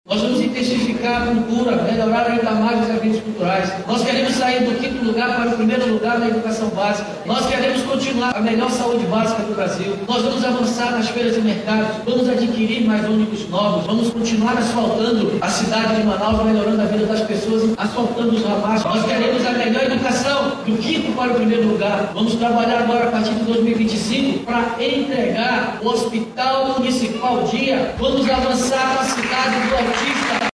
A cerimônia ocorreu nessa quarta-feira 01/01, no salão principal do Teatro Amazonas, no Centro de Manaus.
Durante a posse, o chefe do Executivo Municipal elencou as prioridades para o 2° mandato.